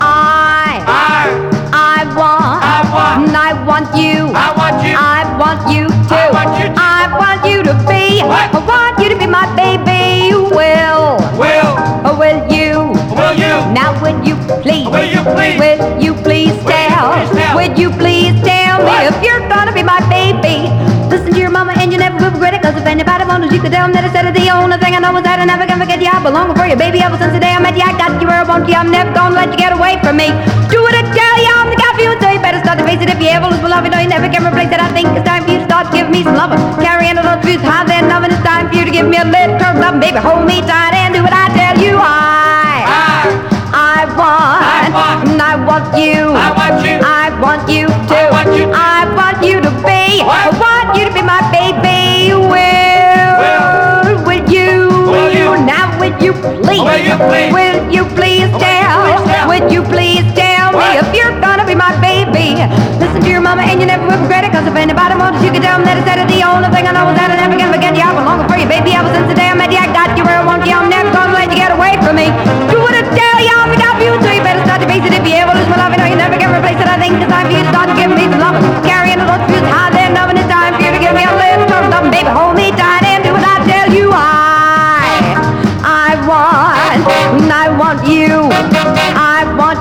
EASY LISTENING / VOCAL / OLDIES / JIVE
まくしたてるような勢いのある早口ヴォーカルと軽快なホーン・アレンジが圧巻です。